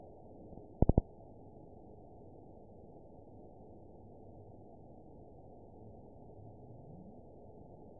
event 922657 date 02/26/25 time 22:53:50 GMT (2 months ago) score 9.21 location TSS-AB01 detected by nrw target species NRW annotations +NRW Spectrogram: Frequency (kHz) vs. Time (s) audio not available .wav